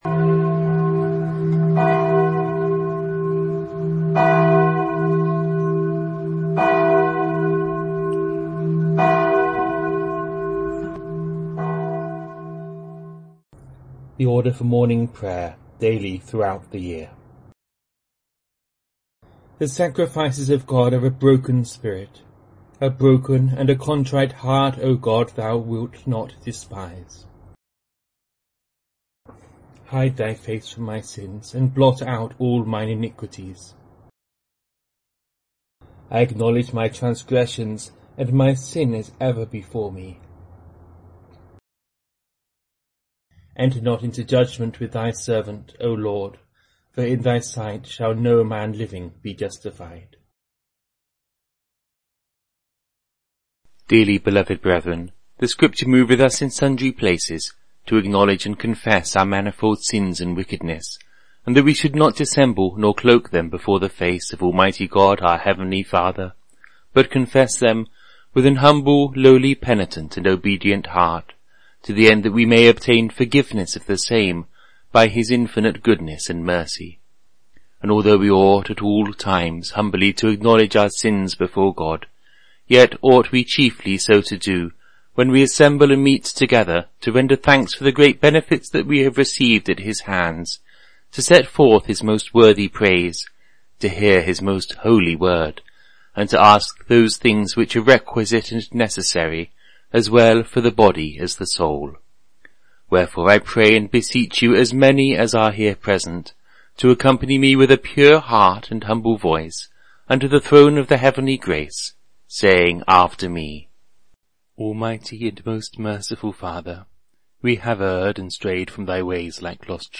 Morning and Evening Prayer from the Book of Common Prayer of 1662 (Anglican)